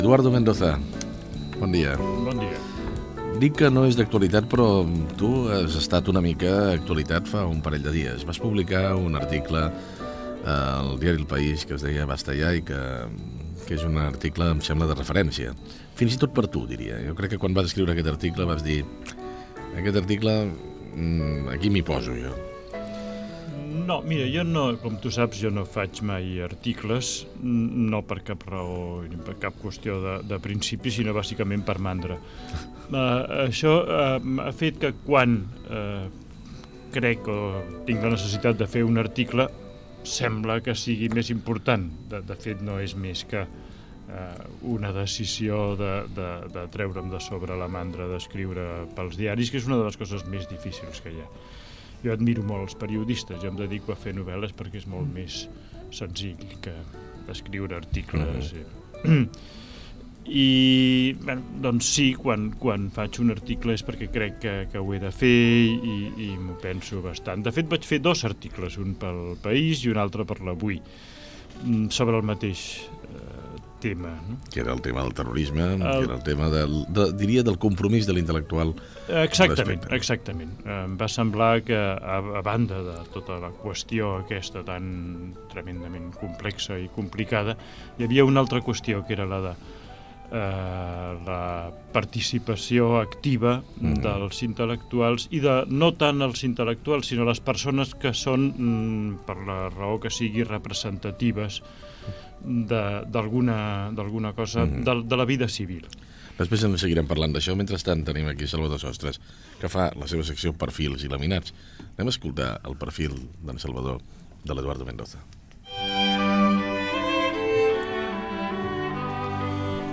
Perfil biogràfic i fragment d'una entrevista a l'escriptor Eduardo Mendoza.
Info-entreteniment